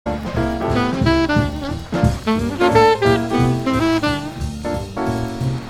1947/Harris Smith Studio, New York NY)より